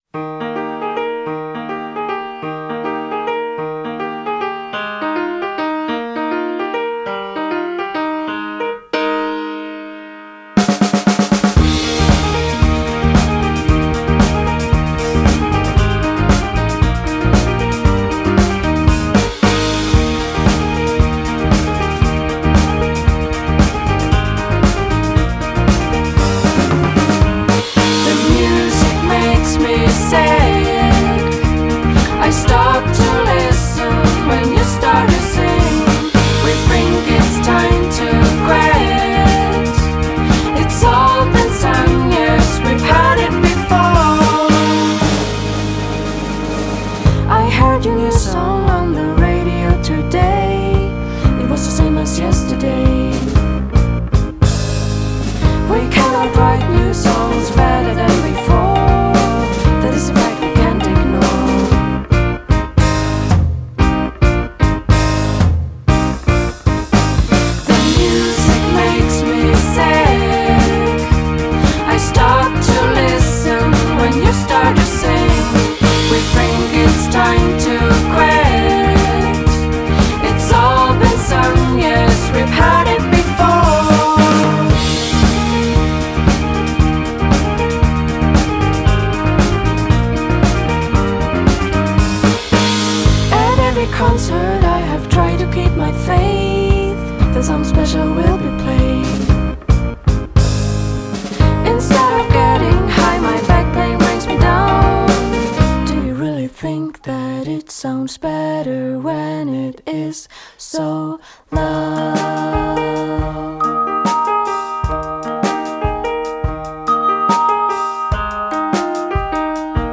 bell'album di pop